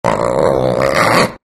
Звуки черной пантеры
Грозный звук рычания пантеры